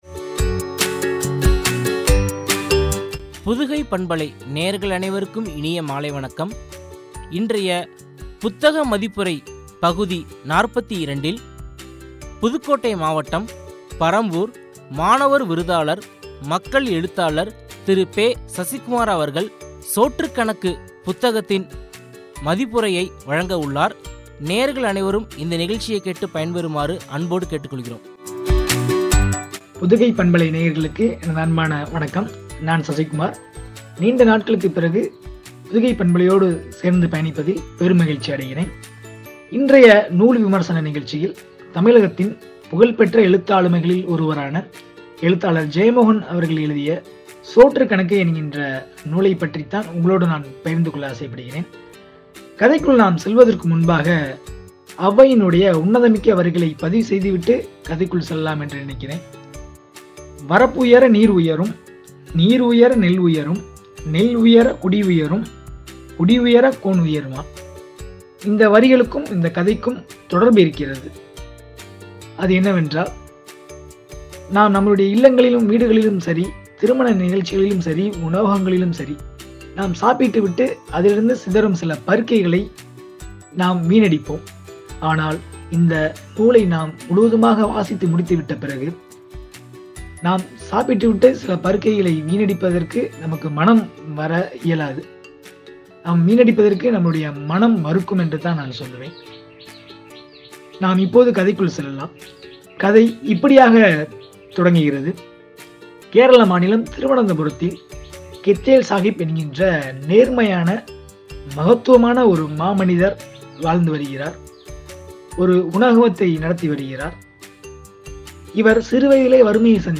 புத்தக மதிப்புரை (பகுதி – 42), குறித்து வழங்கிய உரை.